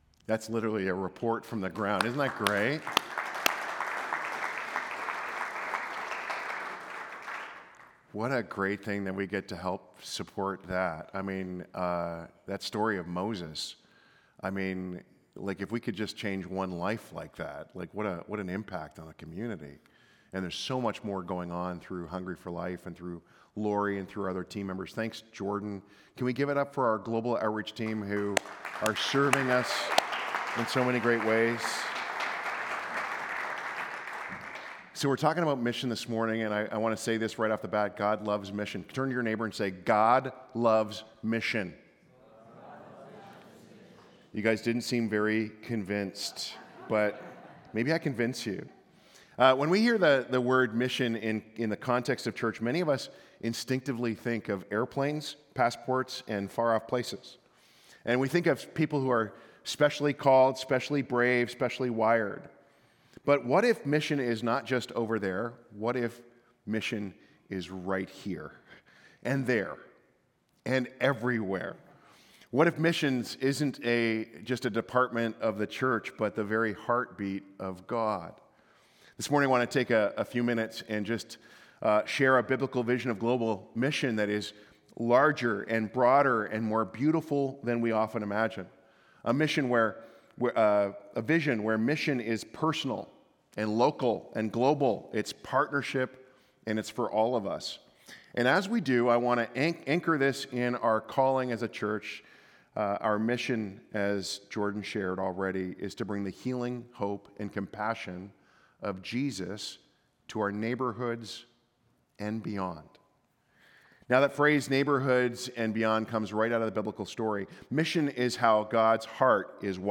Sermon Series – Hillside Church